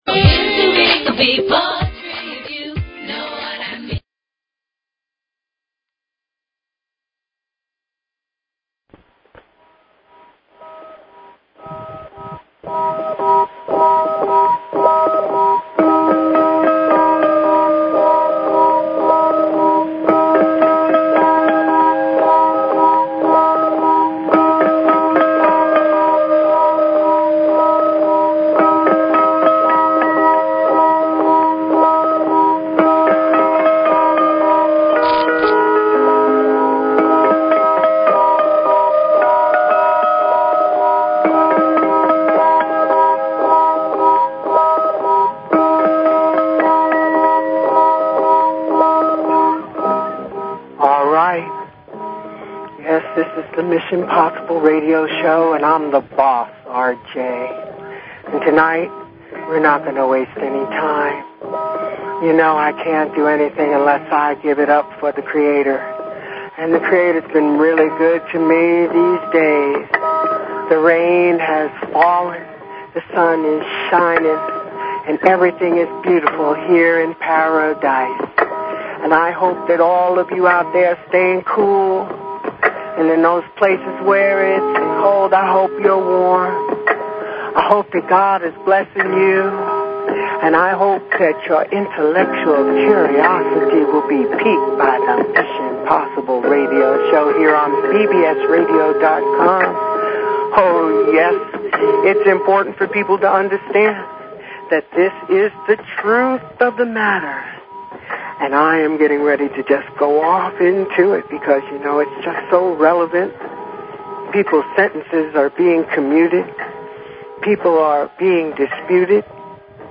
Talk Show Episode, Audio Podcast, Mission_Possible and Courtesy of BBS Radio on , show guests , about , categorized as
Mission Possible Radio (MPR) is a LIVE call-in show